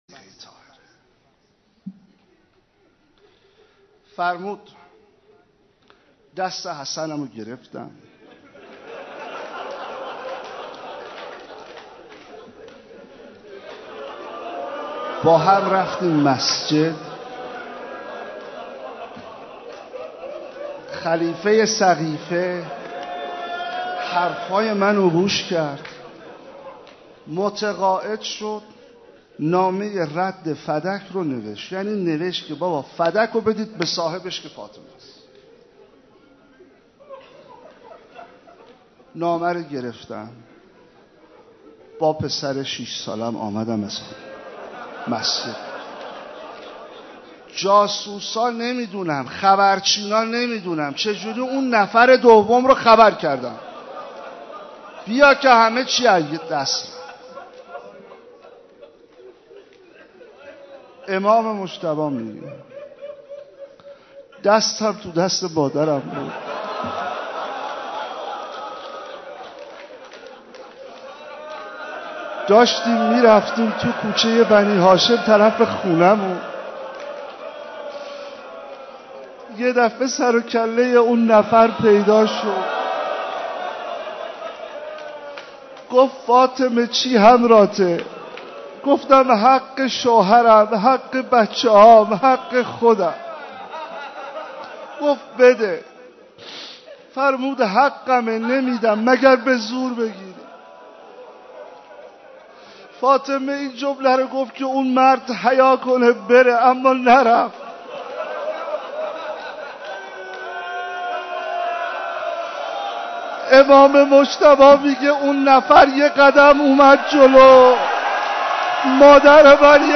روضه
روضه حضرت زهرا(س)
شب بیست و سوم ماه مبارک رمضان